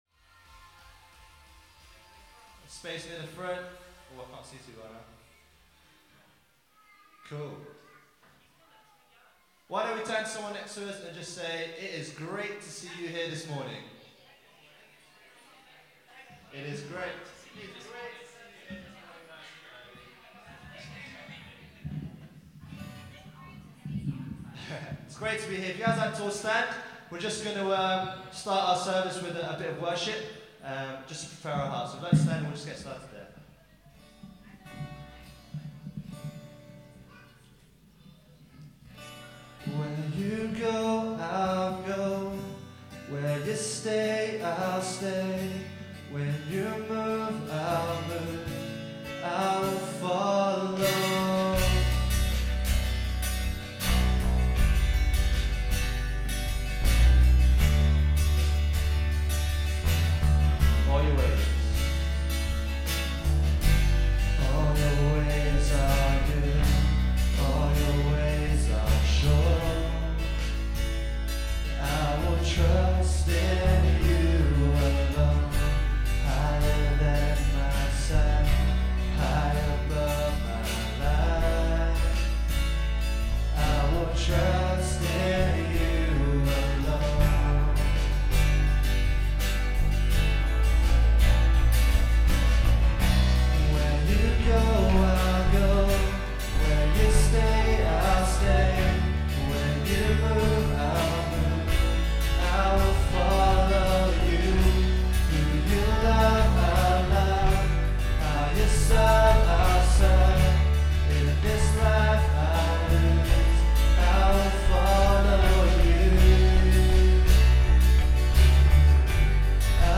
Worship February 1, 2015 – Birmingham Chinese Evangelical Church